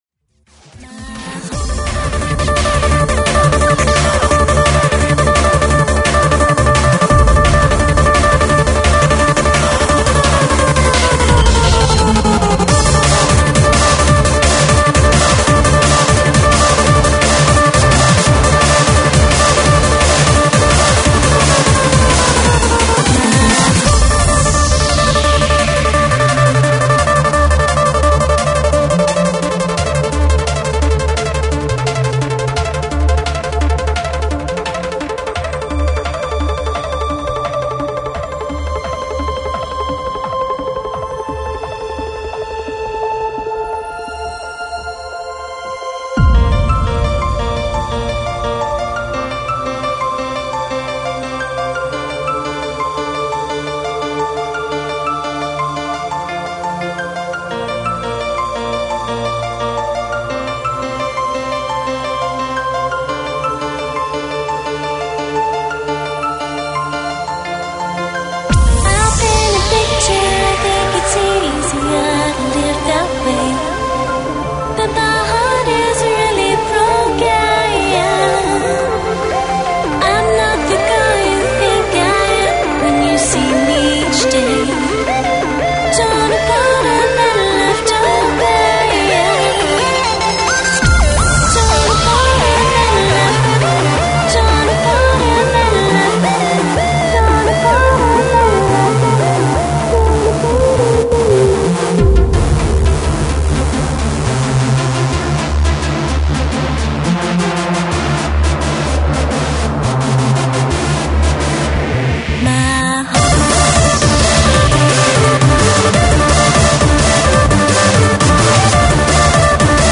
Breakbeat Hardcore